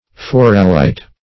Search Result for " foralite" : The Collaborative International Dictionary of English v.0.48: Foralite \For"a*lite\, n. [L. forare to bore + -lite.]